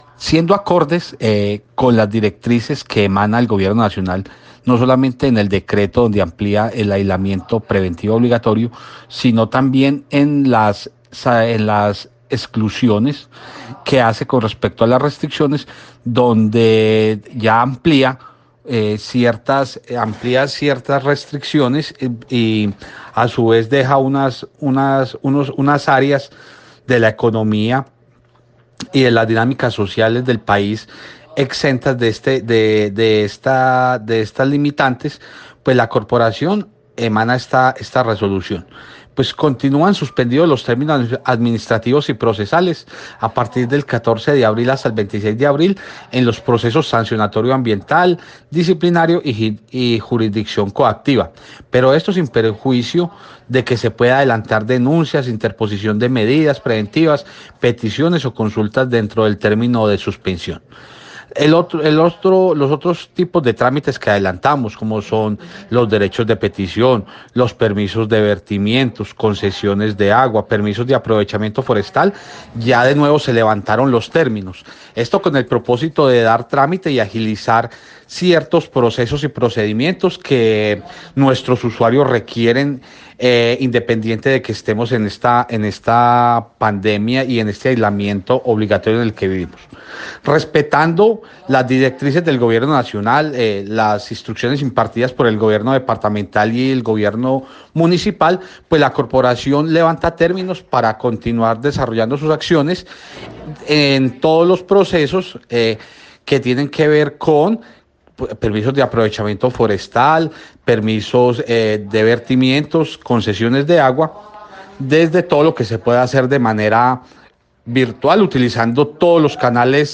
AUDIO DIRECTOR GENERAL DE LA CRQ-JOSÉ MANUEL CORTÉS OROZCO: